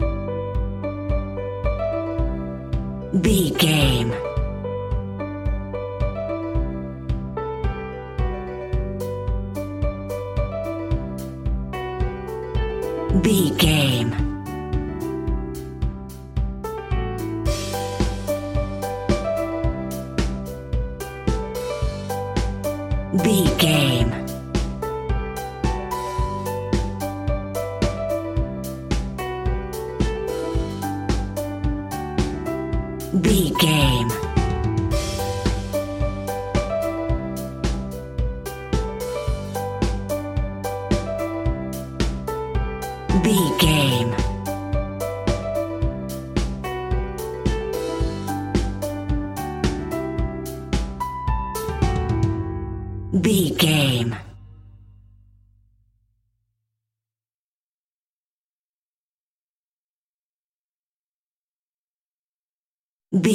Motivation Business Pop Rock Music 60 Sec.
Ionian/Major
pop rock
indie pop
energetic
uplifting
cheesy
upbeat
groovy
guitars
bass
drums
piano
organ